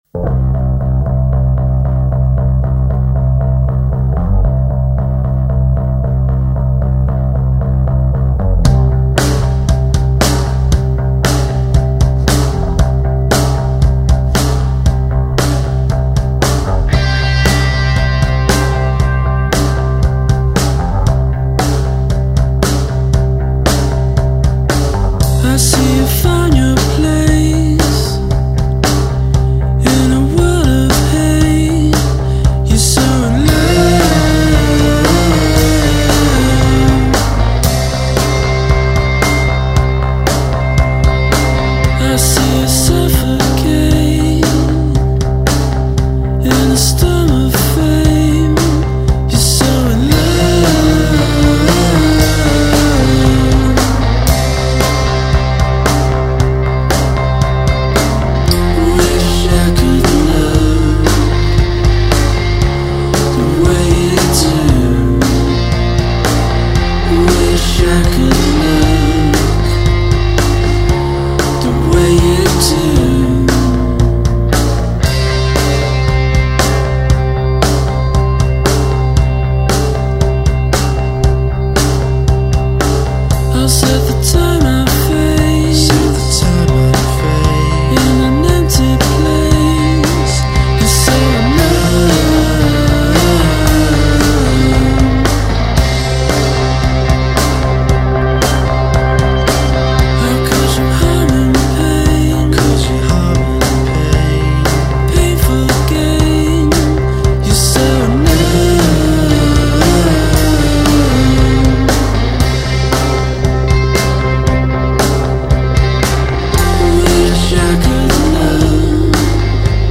demo version’